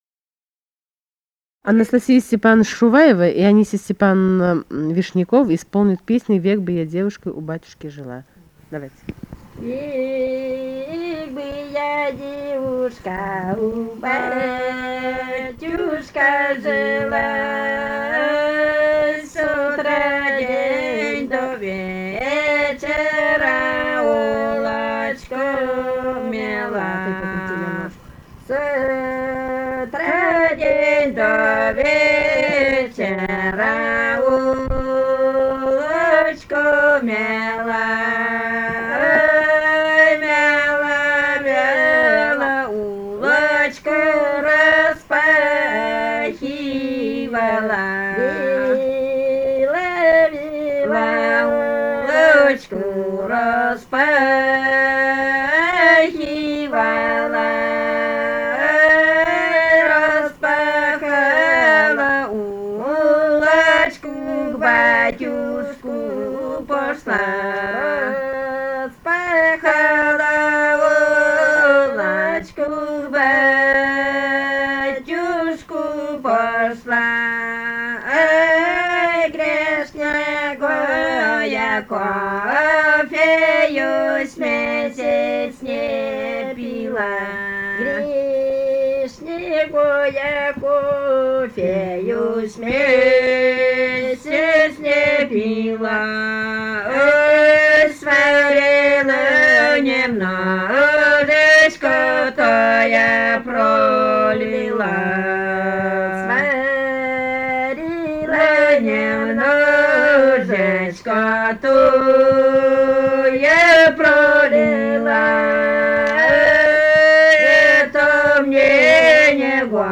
Архангельская область, с. Долгощелье Мезенского района, 1965, 1966 гг.